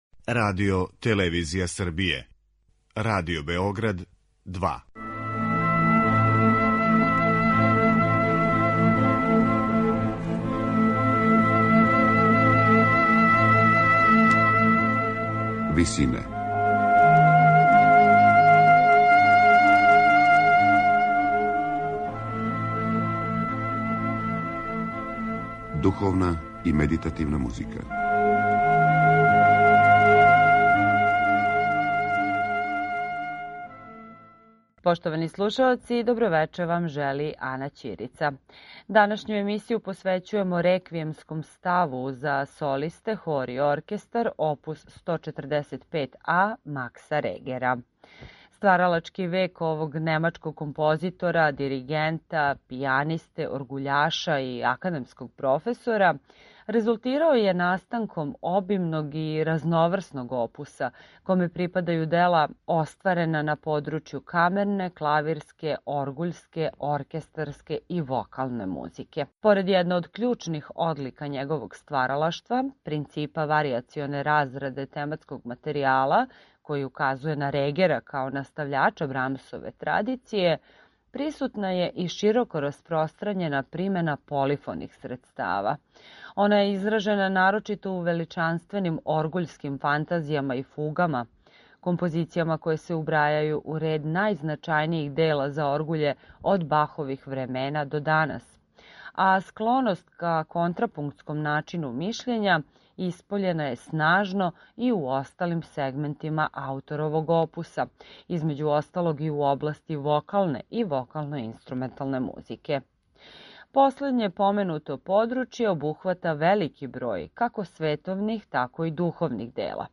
Реквијемски став оп. 145а
медитативне и духовне композиције